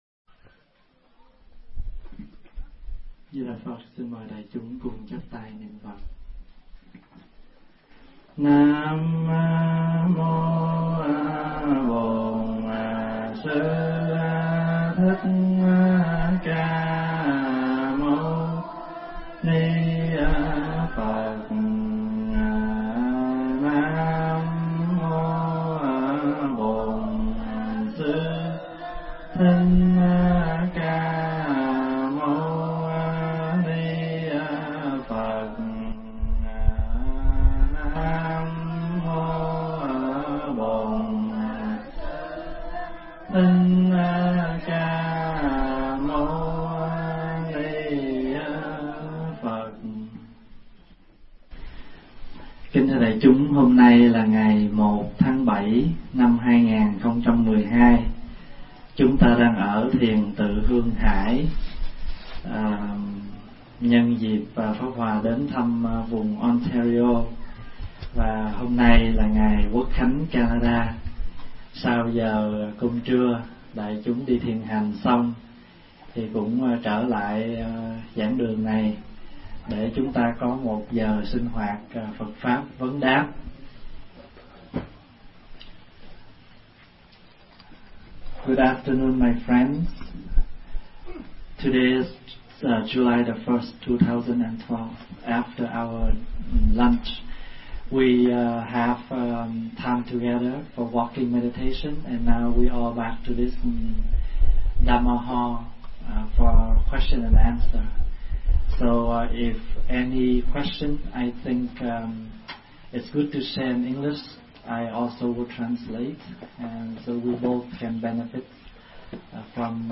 Tải mp3 Pháp Thoại Tịnh Nguyện Tịnh Độ 2 – Đại Đức Thích Pháp Hòa thuyết giảng ngày 1 tháng 7 năm 2012